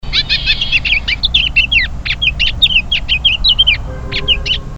Chant enregistré le 05 février 2012, en Chine, province du Guangxi, à Shanli près de la ville de Yizhou.